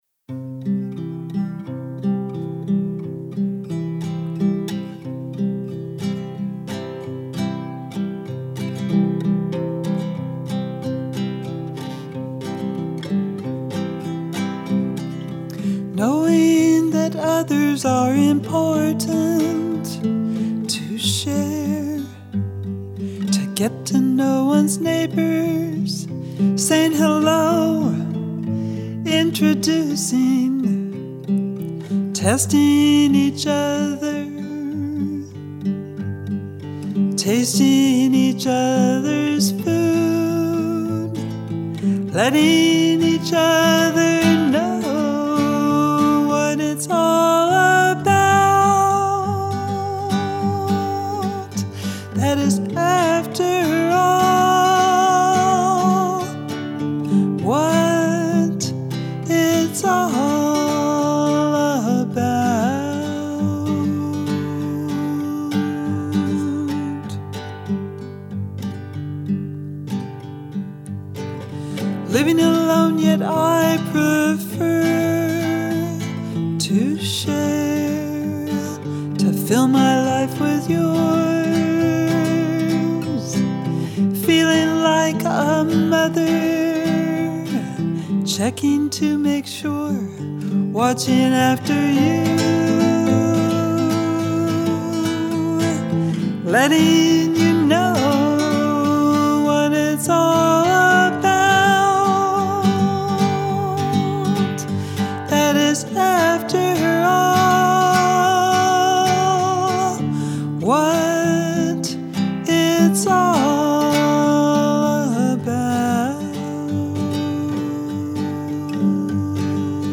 sings this simple song with her guitar in a straightforward manner